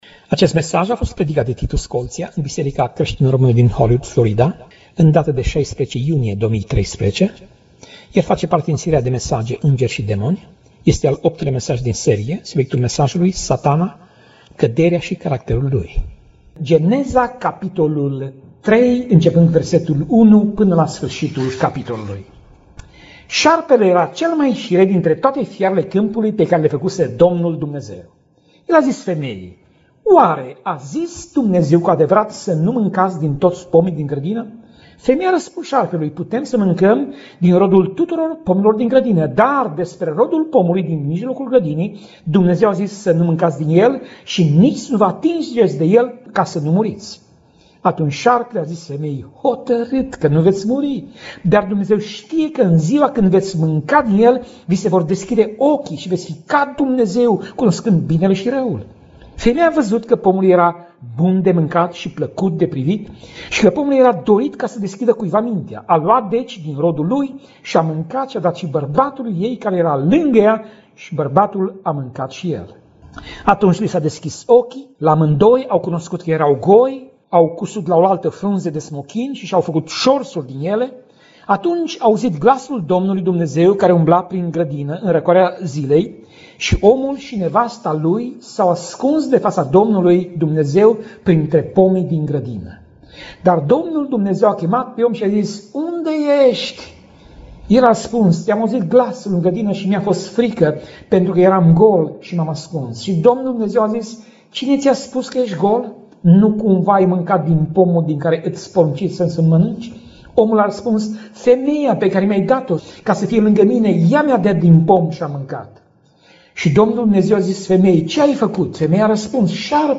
Tip Mesaj: Predica Serie: Ingeri si Demoni